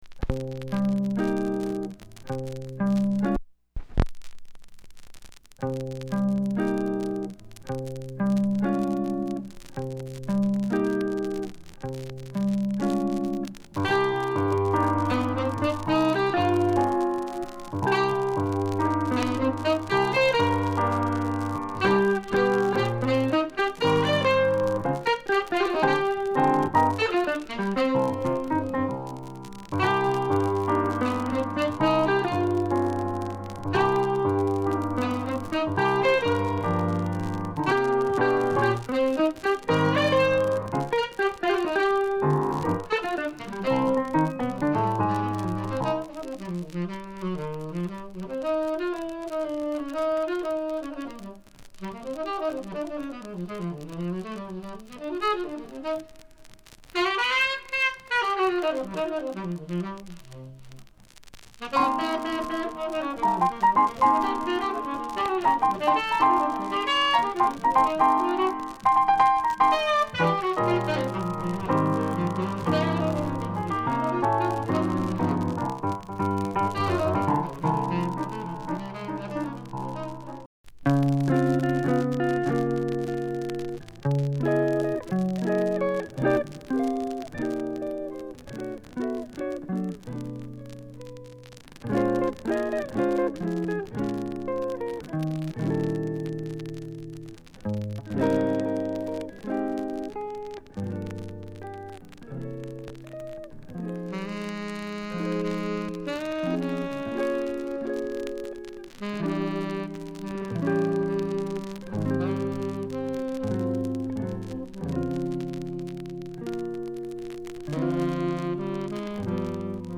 discription:Stereoツリー内溝
ドラムレスなので人気は無いですが、卓越した技工と表現に脱帽の一枚。